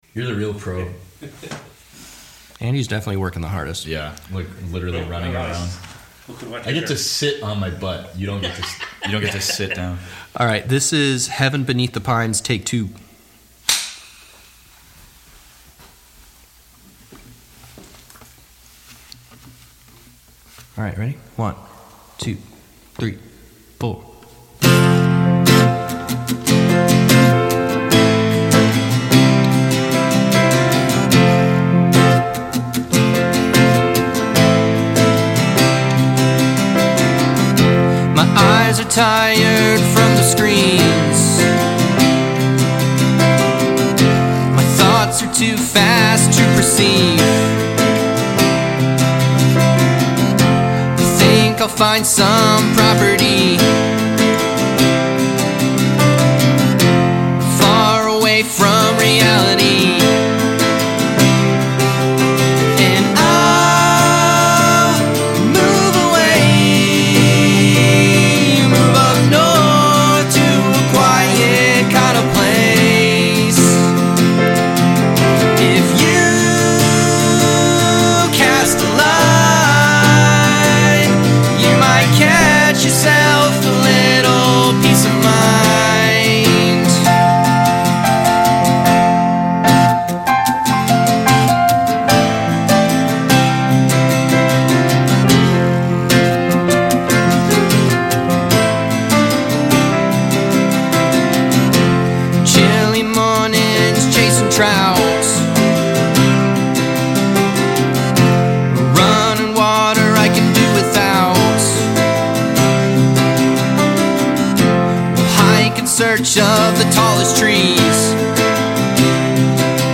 keys